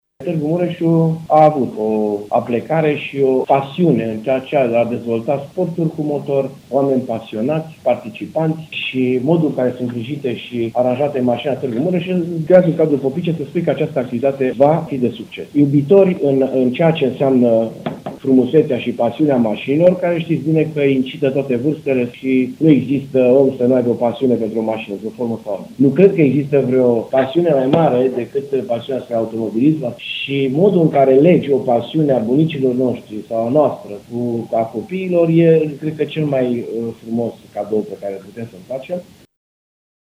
Prin intermediul acestui eveniment va fi promovată imaginea oraşului, susţine primarul municipiului Tîrgu-Mureş, Dorin Florea: